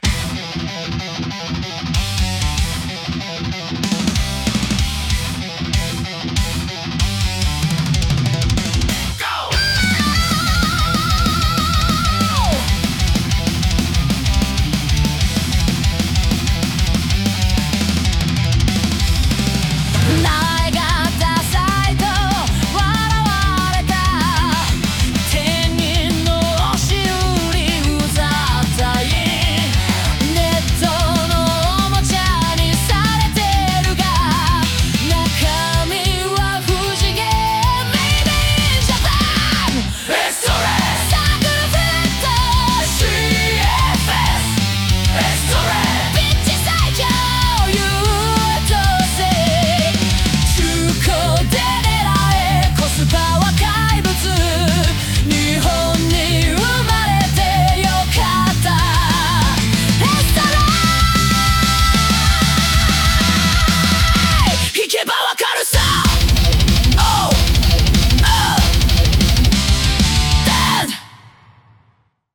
↓要約ソング